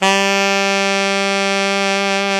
SAX ALTOFF02.wav